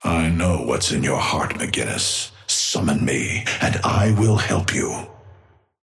Amber Hand voice line - I know what's in your heart, McGinnis. Summon me and I will help you.
Patron_male_ally_forge_start_03.mp3